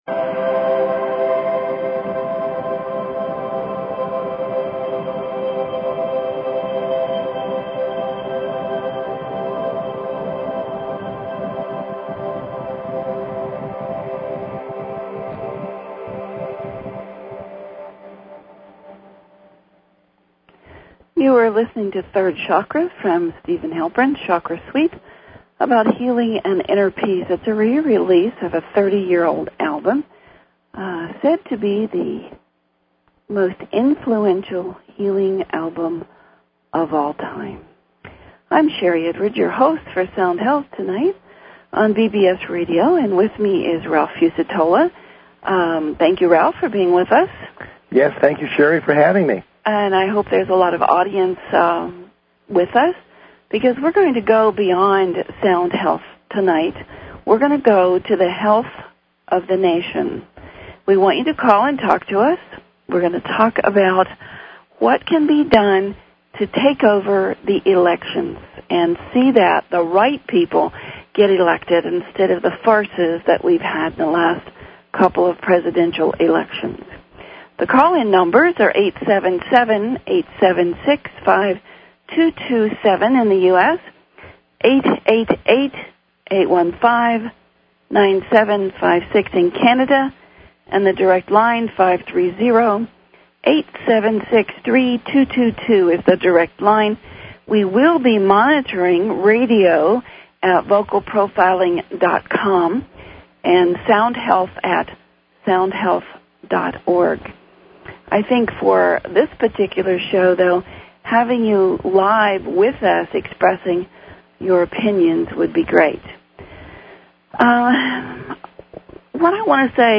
Talk Show Episode, Audio Podcast, News_for_the_Soul and Courtesy of BBS Radio on , show guests , about , categorized as